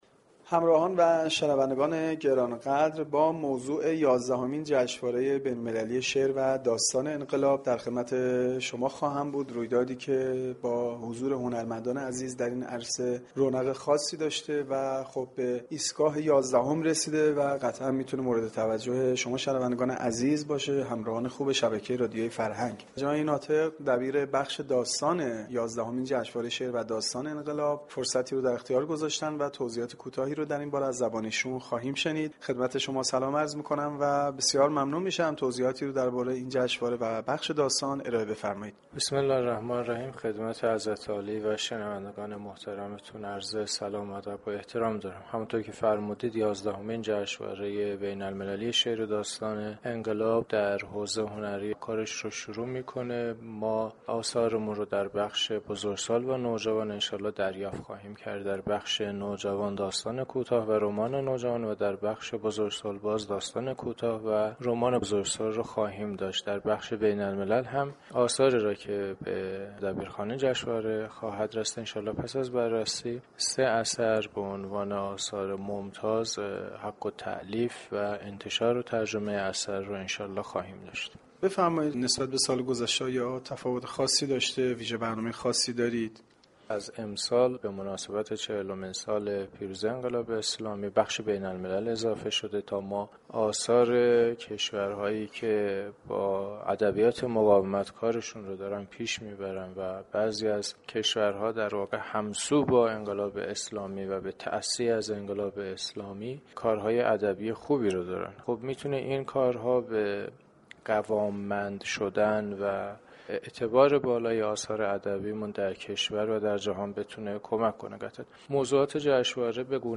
در گفتگو با گزارشگر رادیو فرهنگ